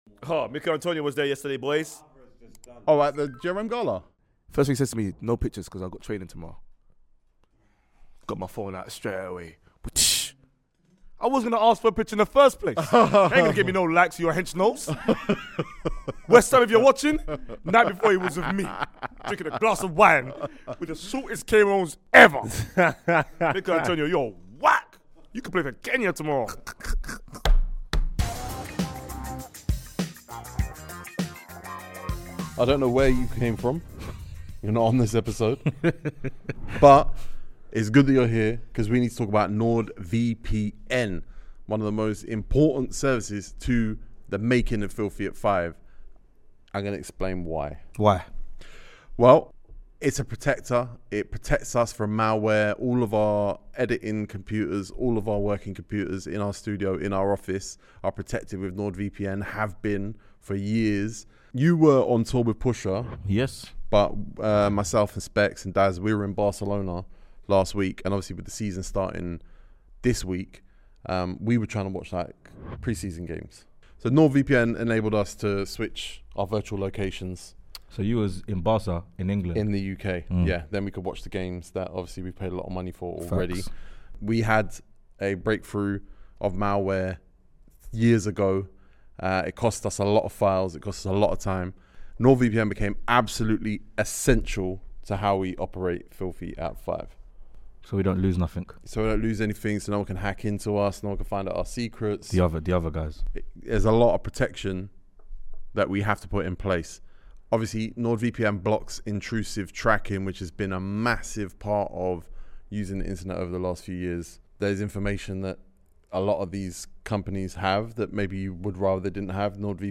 As we enter our 10th season of being Filthy, we wanted to bring a panel together for a very special BONUS EPISODE of FILTHY @ FIVE talking through the end of the pre-season and each and every one of the first 10 games of the Premier League season.